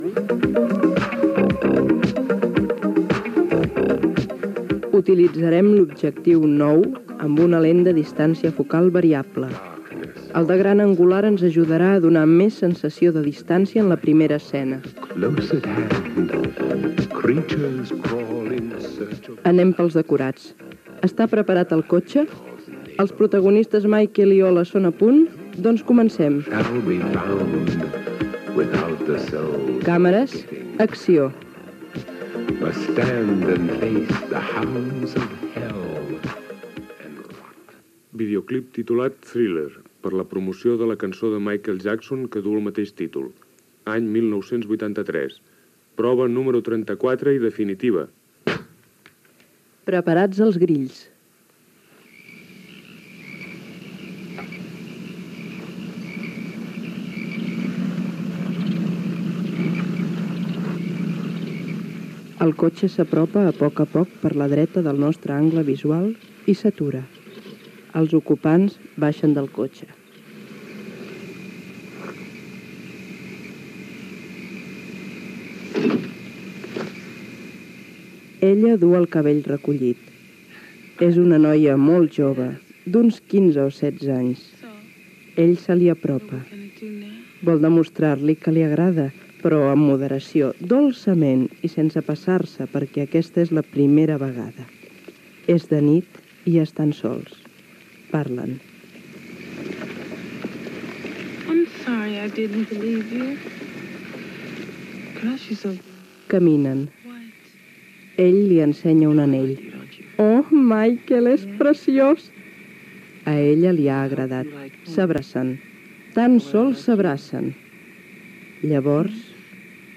El rodatge del videoclip de la cançó "Thilller" (1983) de Michael Jackson Gènere radiofònic Entreteniment